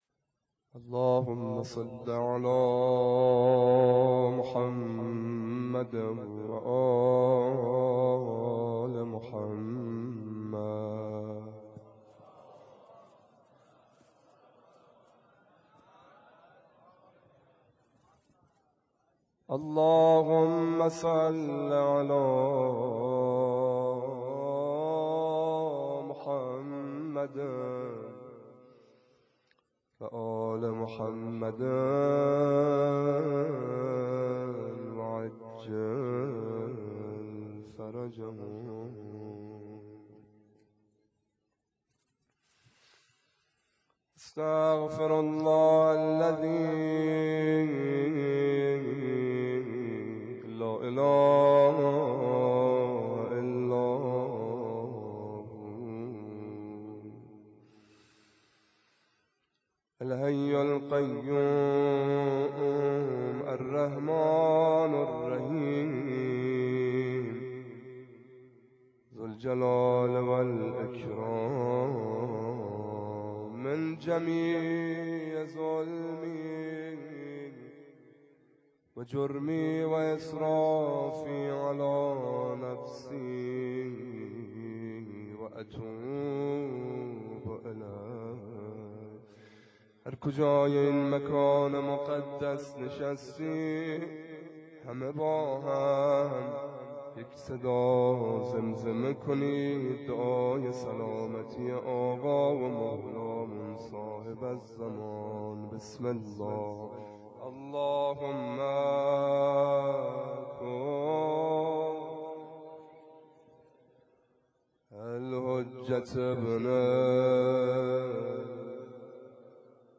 شب یازدهم _ روضه